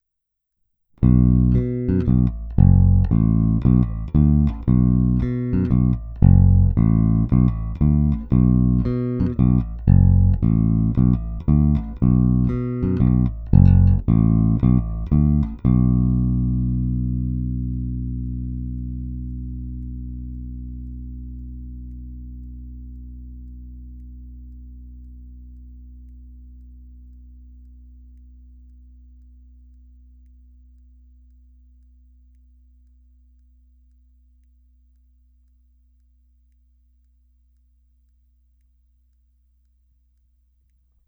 Jestliže zvukový projev lípy je obecně měkčí, temnější, jelikož se jedná o měkké dřevo, snímače tento projev upozadily, zvuk je naprosto klasický průrazně jazzbassový, s pořádnou porcí kousavých středů.
Není-li uvedeno jinak, následující nahrávky jsou provedeny rovnou do zvukové karty, jen normalizovány, jinak ponechány bez úprav.
Oba snímače